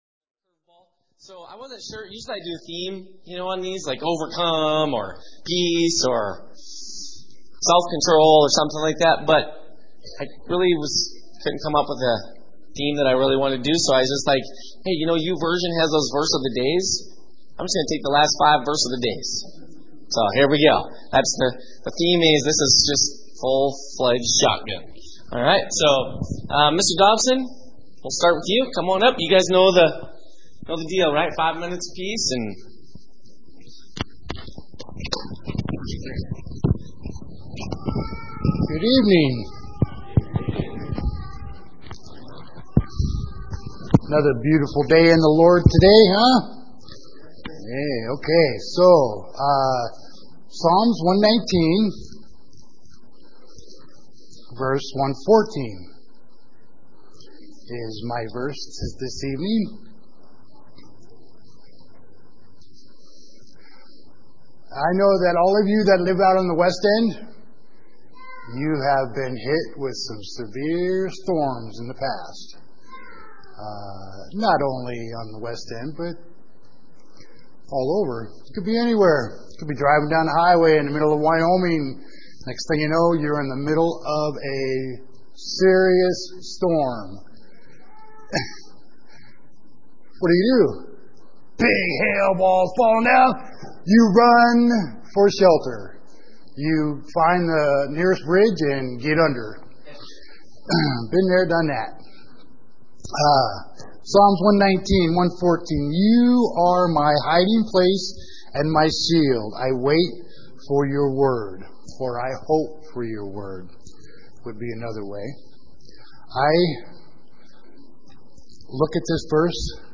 Listen in on a special treat of unscheduled shotgun preaching!